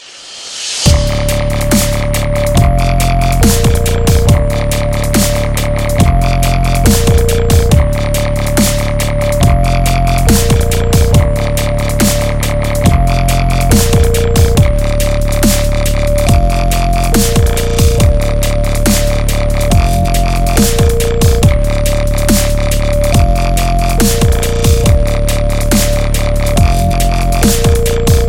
例子 寒冷的步骤槽
描述：140 BPM。寒冷的步骤。在这里获得循环播放
Tag: 140 bpm Dubstep Loops Groove Loops 4.76 MB wav Key : Unknown